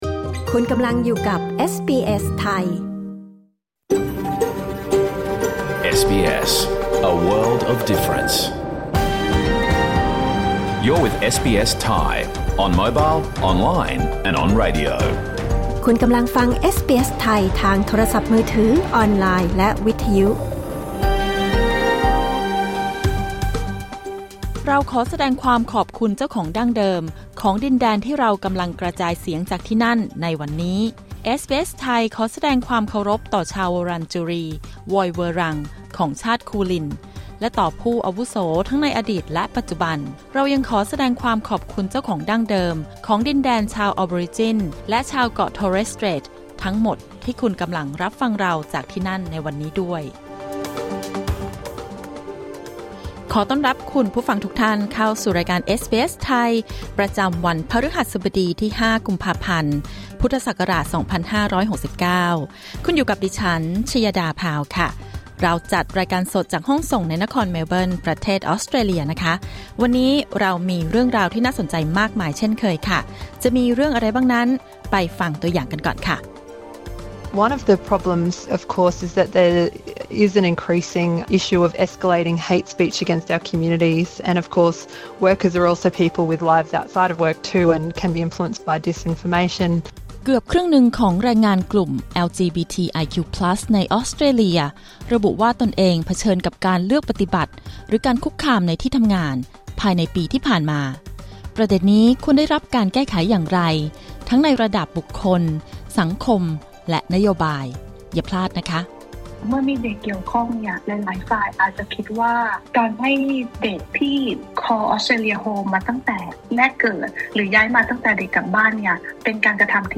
รายการสด 5 กุมภาพันธ์ 2569